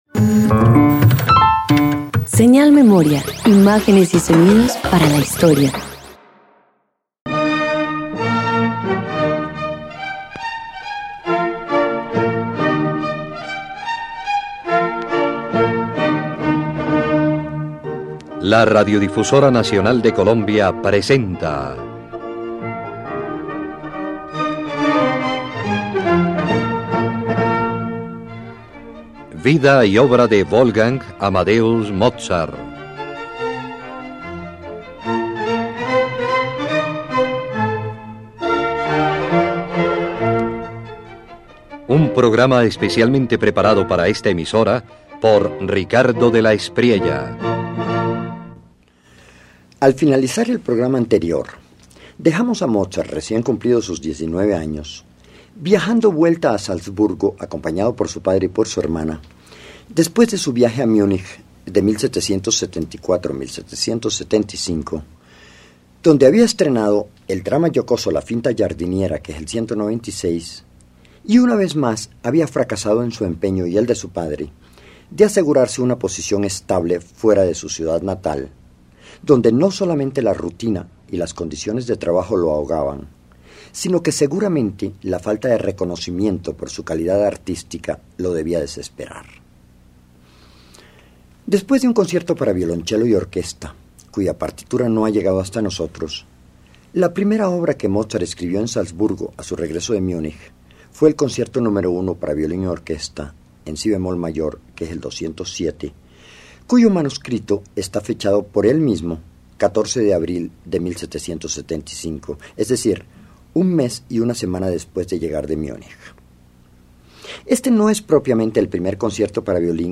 De regreso en Salzburgo, Mozart compone su Concierto para violín n.º 1 en si bemol mayor, una obra luminosa y galante donde despliega frescura melódica y elegancia juvenil, alejándose del virtuosismo para celebrar la pura musicalidad.